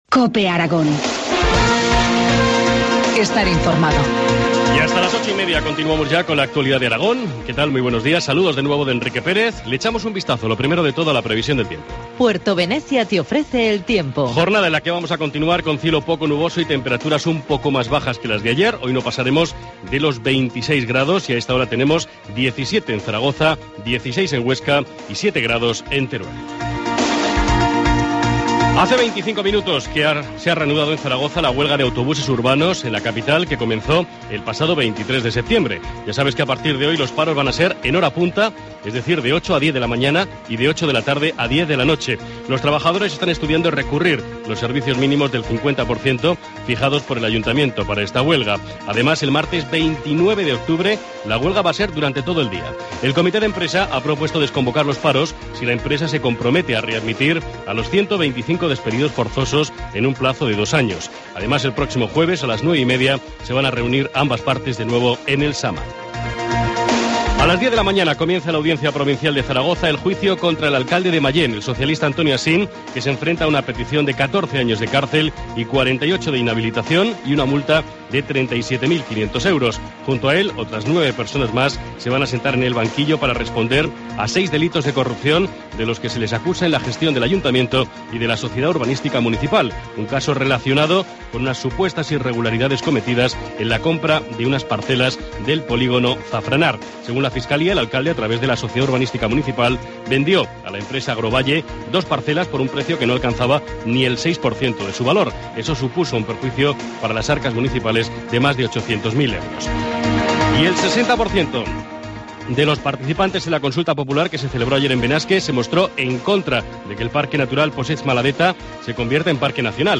Informativo matinal, lunes 21 de octubre, 8.25 horas